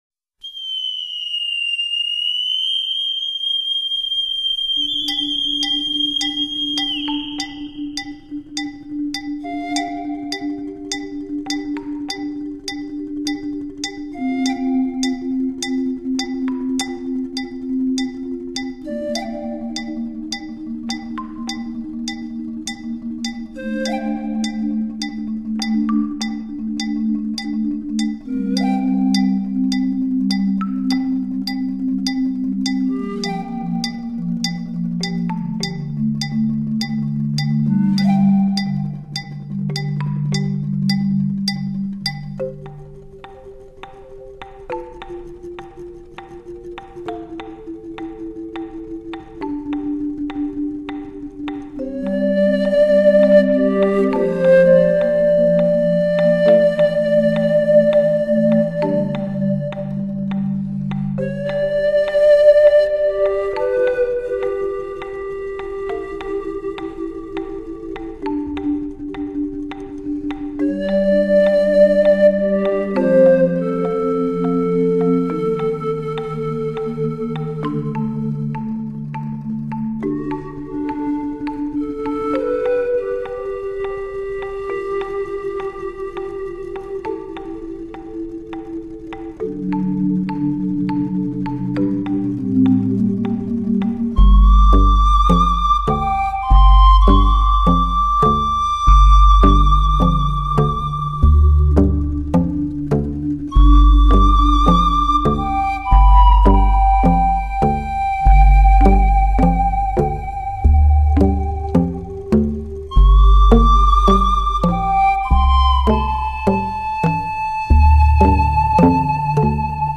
所有的声音，均来自二十余种纯粹的竹制乐器组合，绝无竹之异类。
全竹乐器演奏，绿色声音，远离红尘，首次呈现竹之巨幅
超重低音，浩瀚动感 绝无仅有。